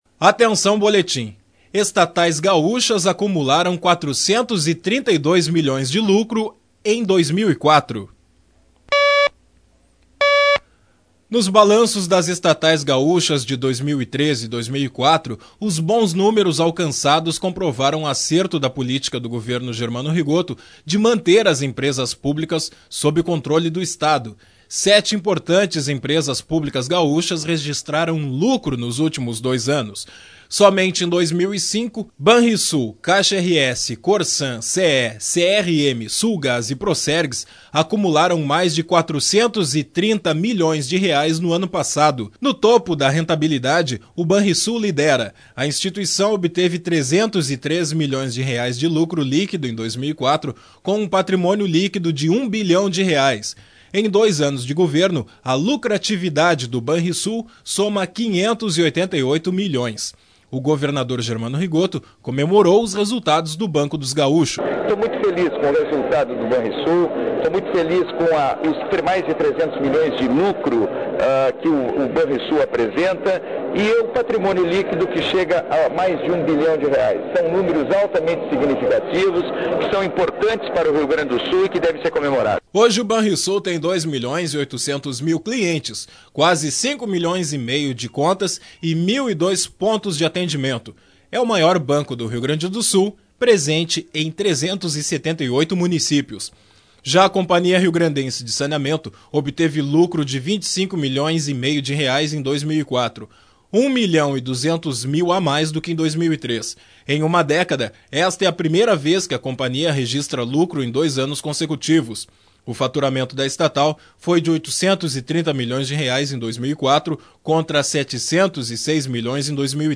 Sete importantes empresas públicas gaúchas registraram lucro nos últimos dois anos. Somente em 2005, Banrisul, Caixa RS, Corsan, CEEE, CRM, Sulgás e Procergs acumularam mais de R$ 430 milhões no ano passado. Sonoras: governador Germano Rigotto, preside